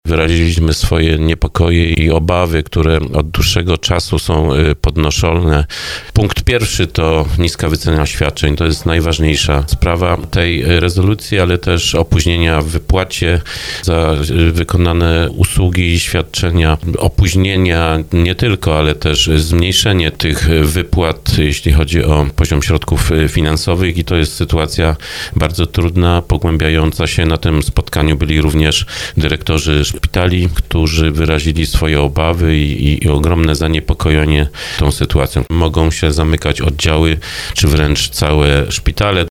Mówił o tym w audycji Słowo za Słowo starosta dąbrowski Lesław Wieczorek.